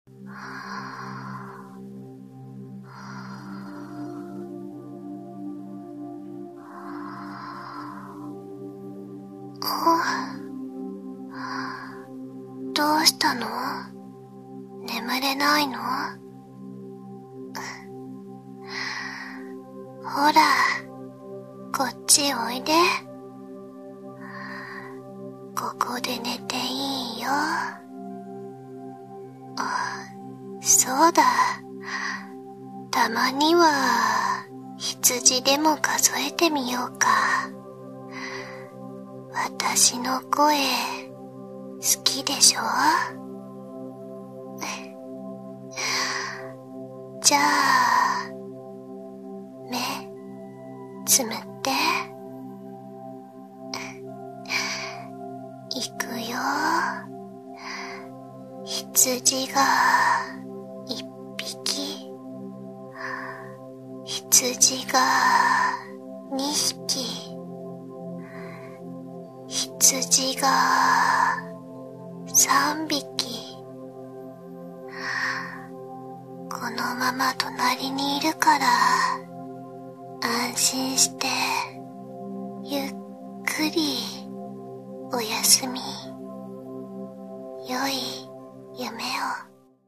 朗読台本「おやすみCD」